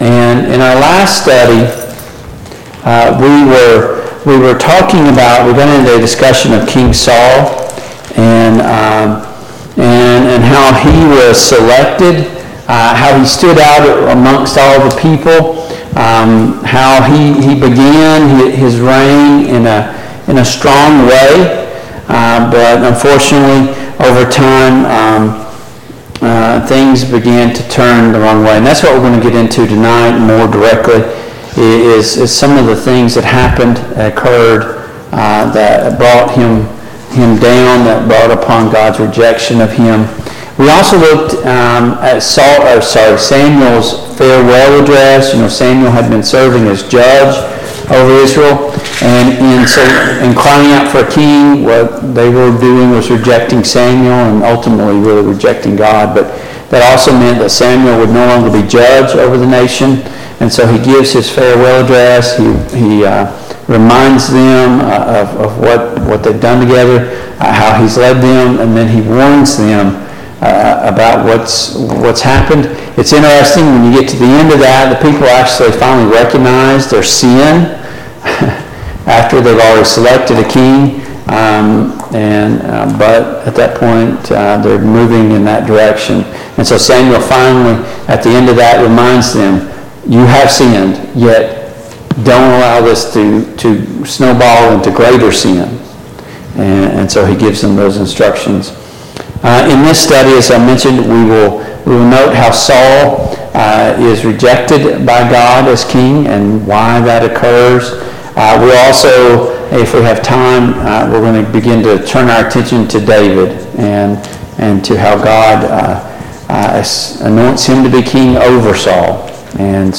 Passage: I Samuel 14, I Samuel 15 Service Type: Mid-Week Bible Study